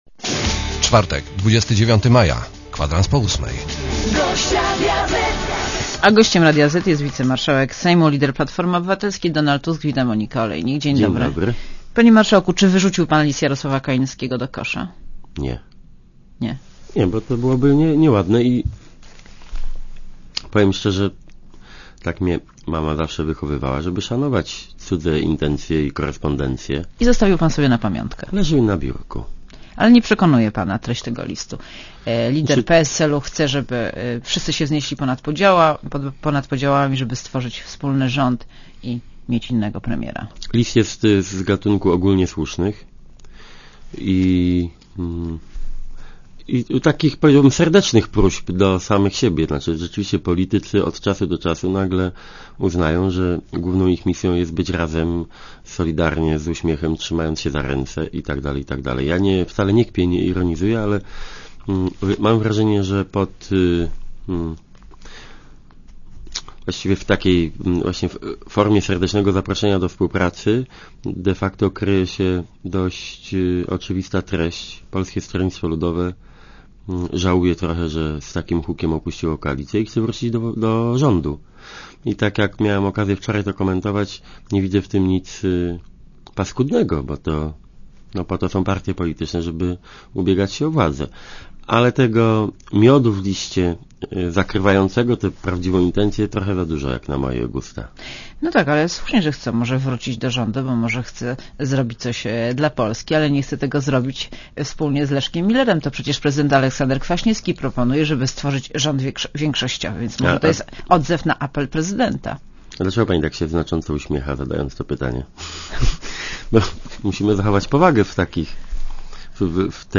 Donald Tusk w Radiu Zet (RadioZet)